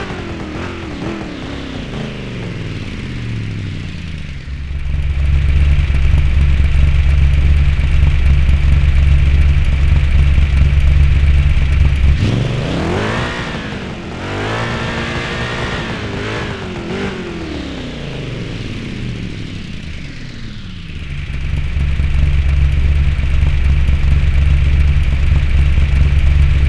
Index of /server/sound/vehicles/sgmcars/buggy
throttle_off.wav